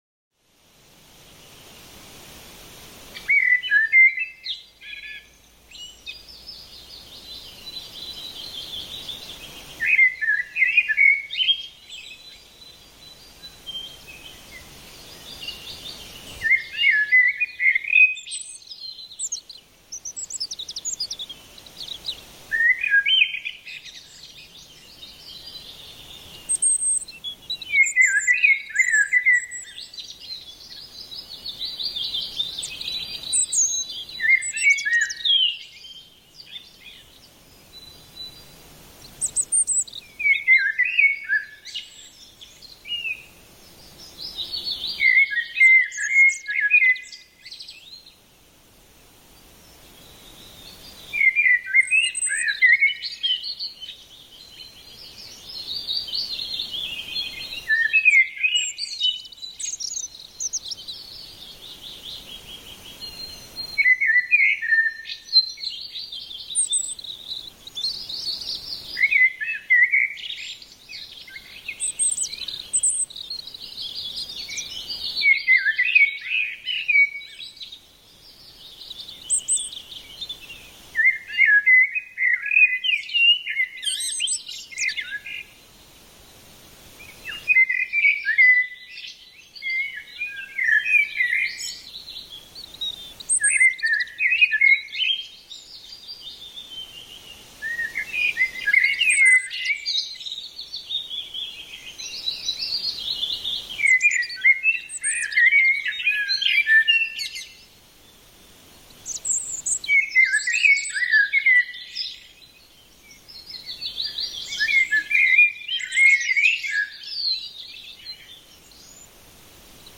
MAGISCHES FRÜHLINGSLICHT: Blumenwald-Frühling mit Vogelkonzert
Naturgeräusche